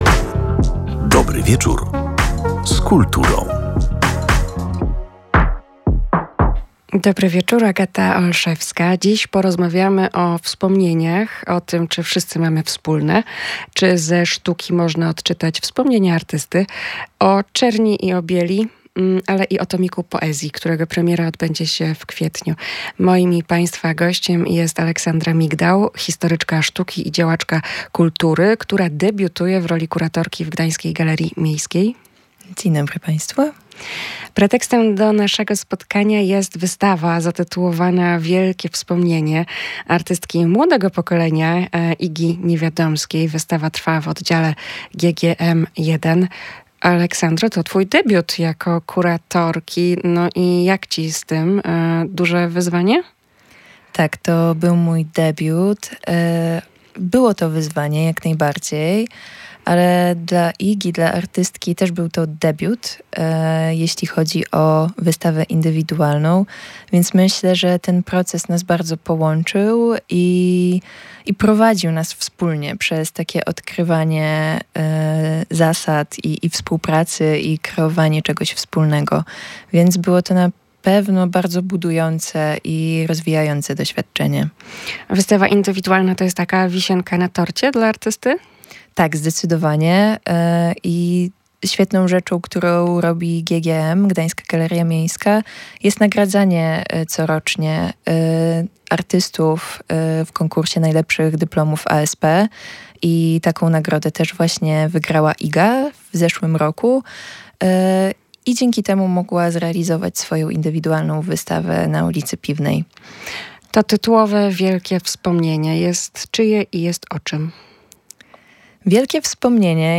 historyczka sztuki i działaczka kultury